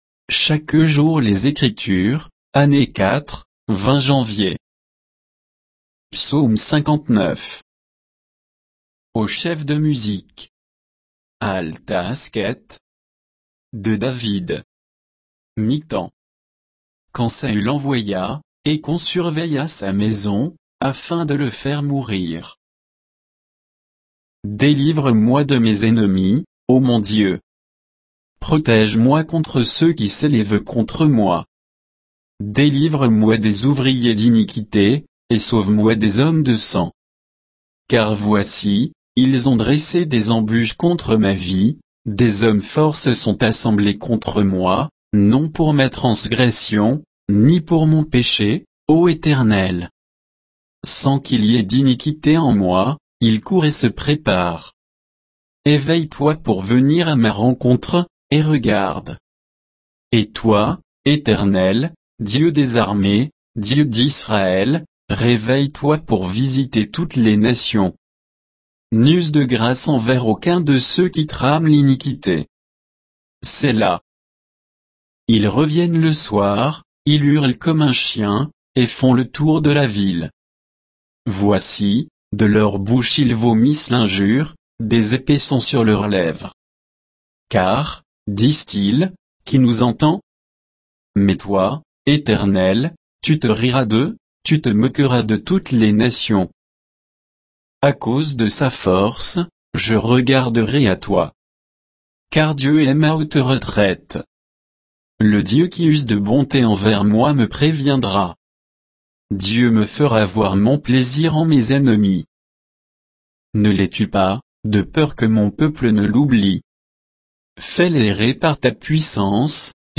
Méditation quoditienne de Chaque jour les Écritures sur Psaume 59